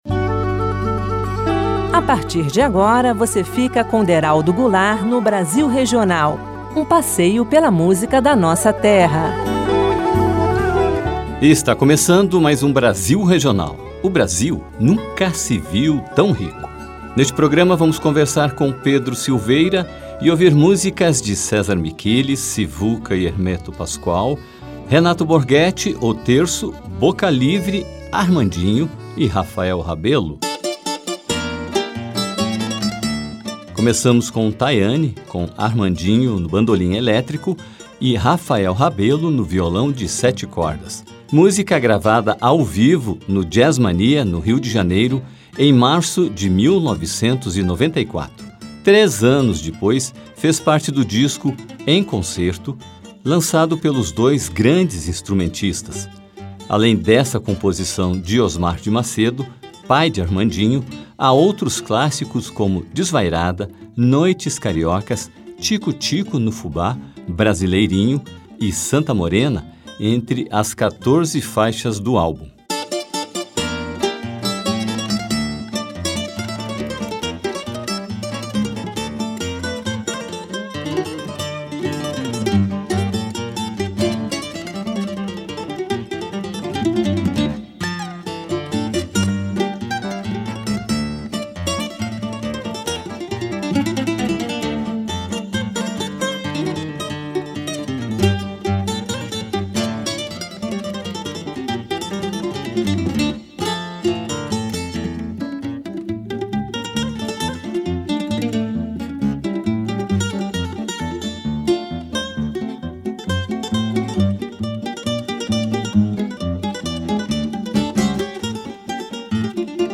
produtor e compositor de música instrumental.